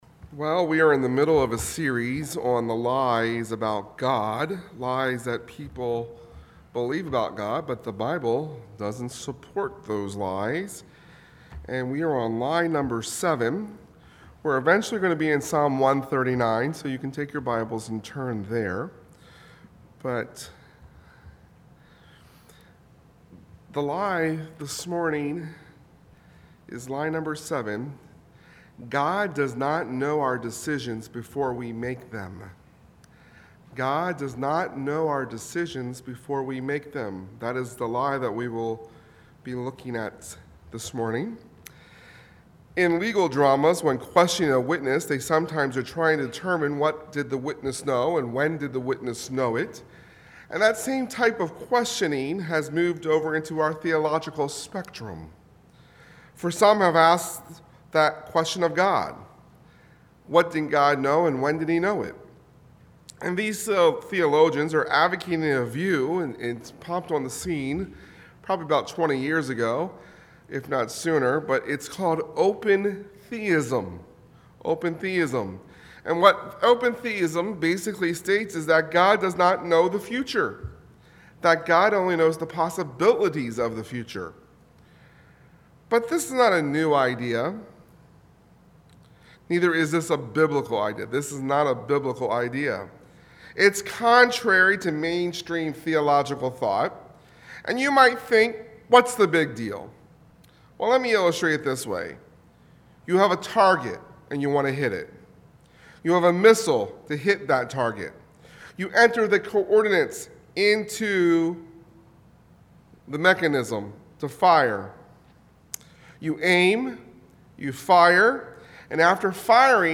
Passage: Psalm 139:1-24 Service: Sunday Morning